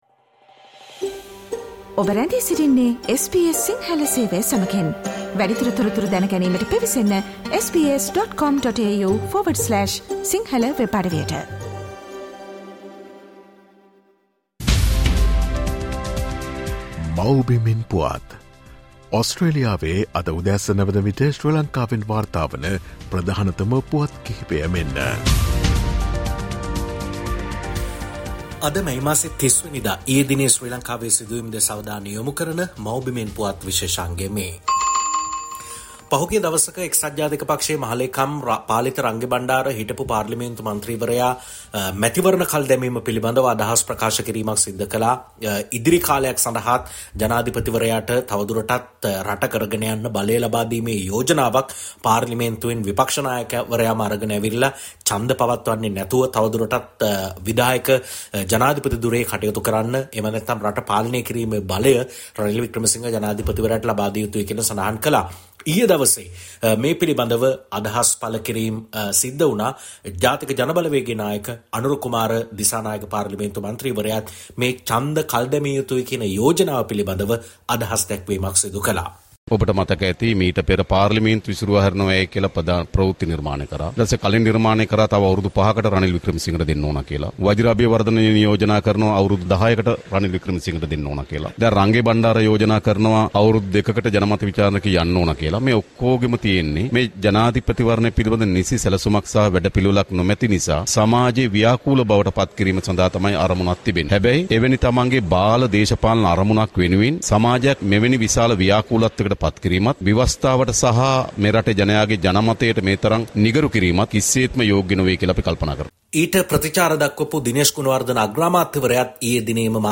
The latest news reported from Sri Lanka as of this morning in Australia time from the “Homeland News” feature